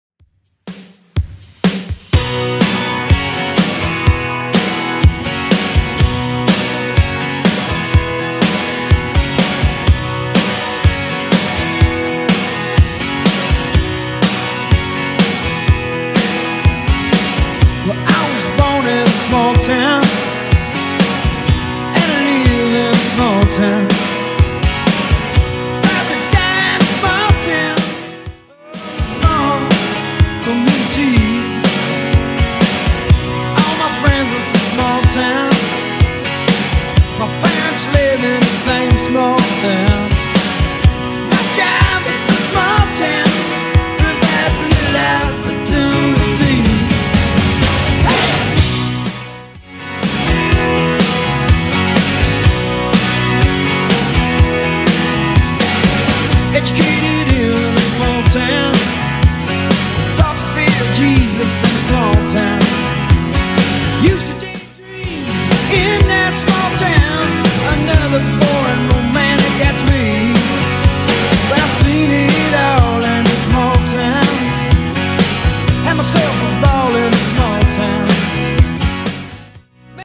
Genre: 80's
BPM: 120